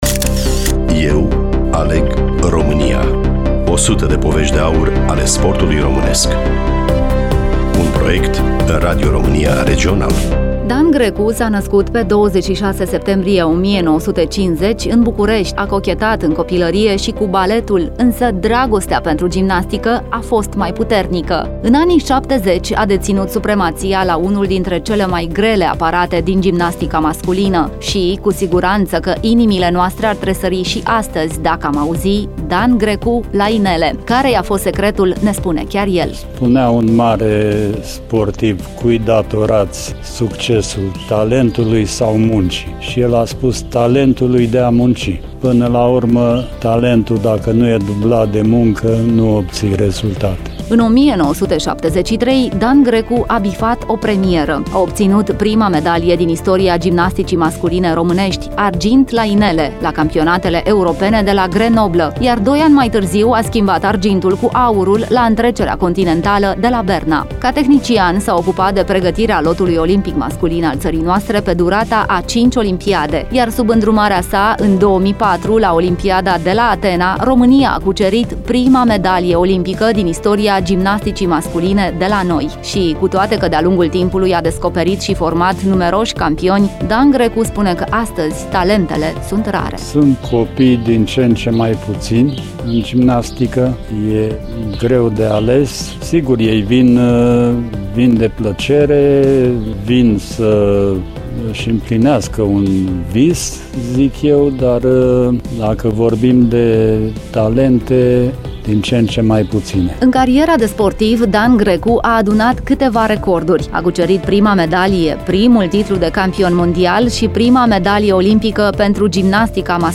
Studioul Radio România Constanţa